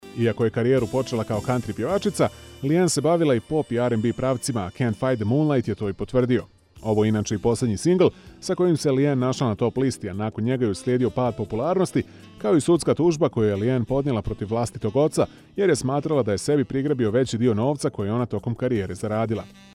Boşnakca Seslendirme
Erkek Ses